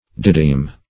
didym - definition of didym - synonyms, pronunciation, spelling from Free Dictionary Search Result for " didym" : The Collaborative International Dictionary of English v.0.48: Didym \Di"dym\, n. (Chem.)